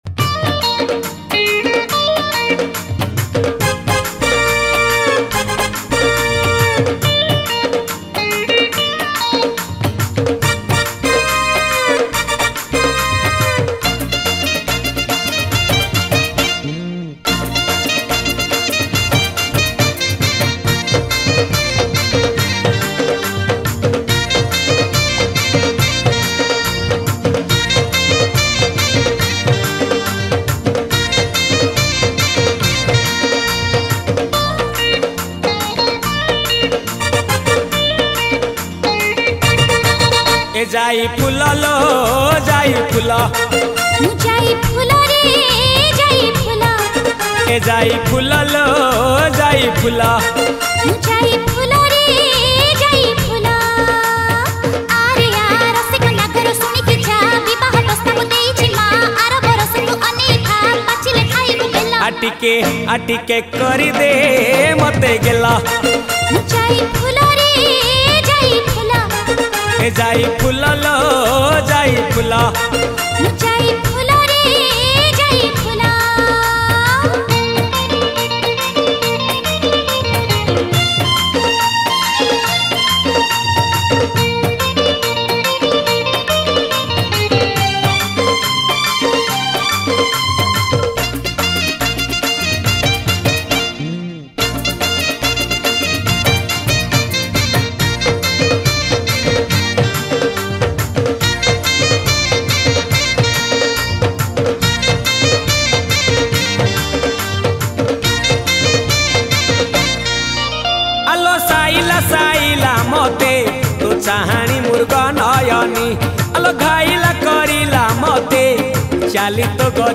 Odia Old Hits Mp3 Song